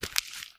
STEPS Newspaper, Sneak 02.wav